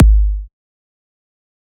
EDM Kick 5.wav